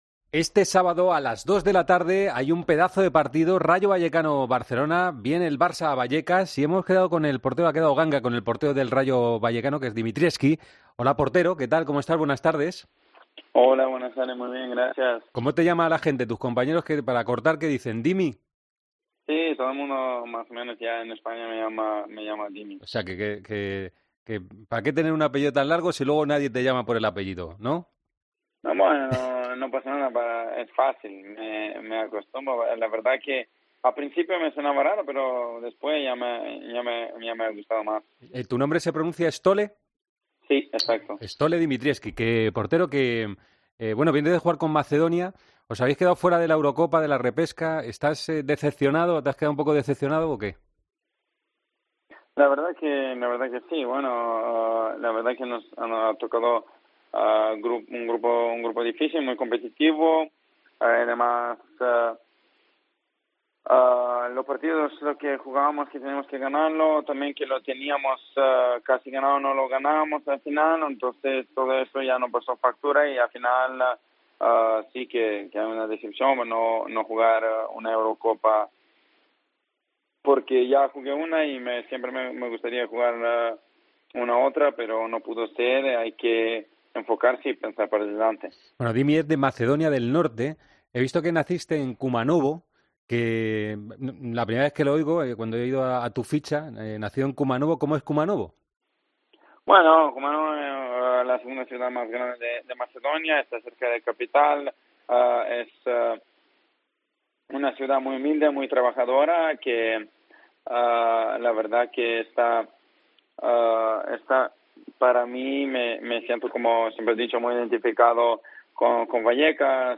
El portero macedonio aclaró en Deportes COPE su futuro y la actualidad del equipo antes del encuentro contra el FC Barcelona este sábado en Vallecas.
Stole Dimitrievski habló en Deportes COPE antes del partido del sábado entre el Rayo Vallecano y el FC Barcelona. El guardameta aclaró su futuro y repasó la actualidad del equipo previa al encuentro contra el conjunto blaugrana.